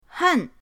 hen4.mp3